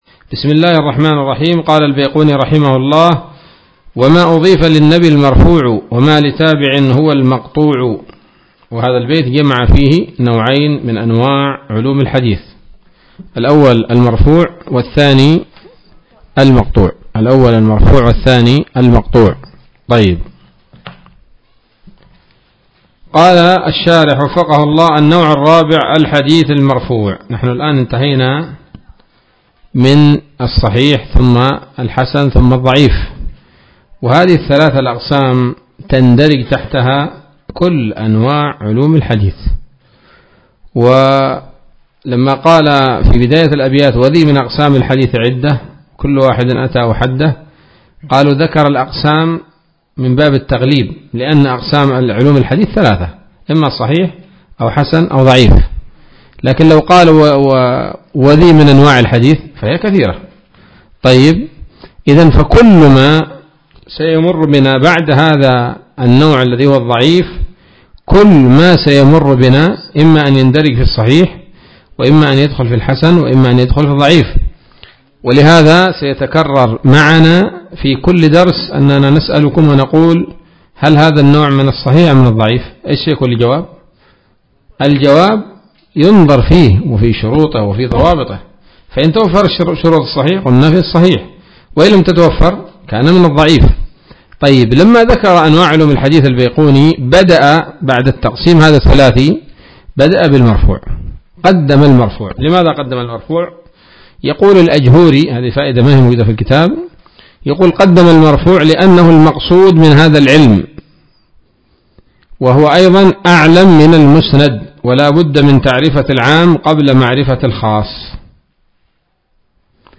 الدرس العاشر من الفتوحات القيومية في شرح البيقونية [1444هـ]